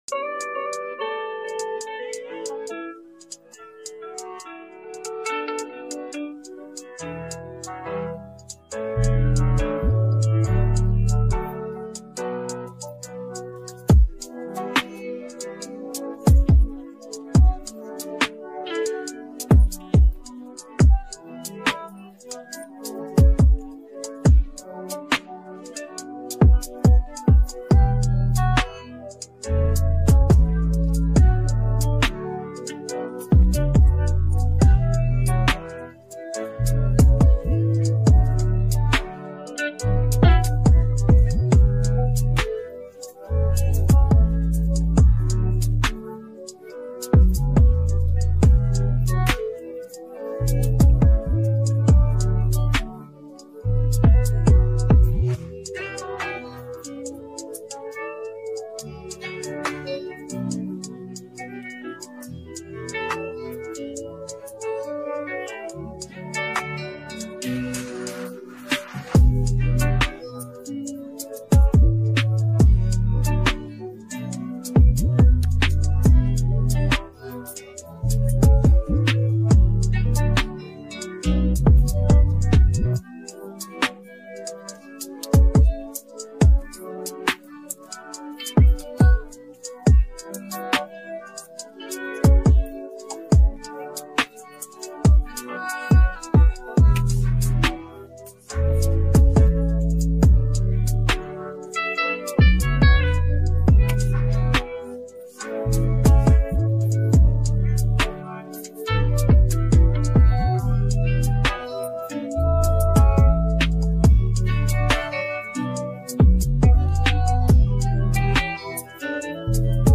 This is the instrumental of the song.